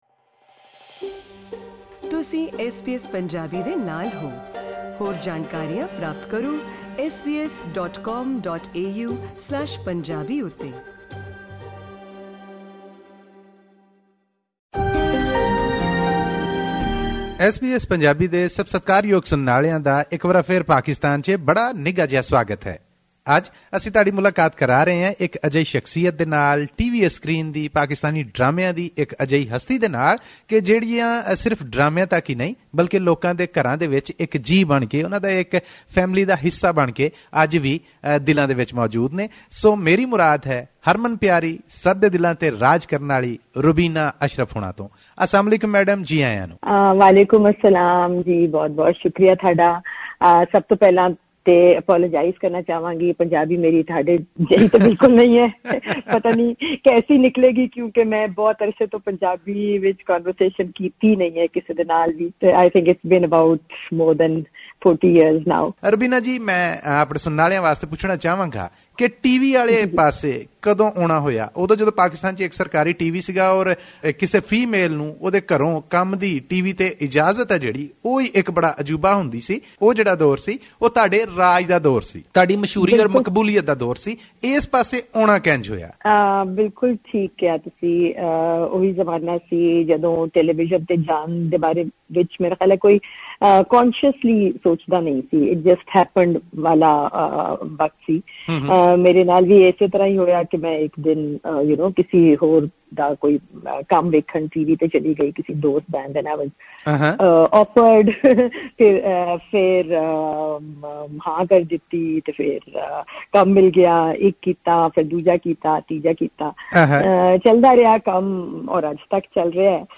In an exclusive interview with SBS Punjabi the veteran actress talked about her first break on television, which spawned a successful career on both the small and the big screen for over three decades.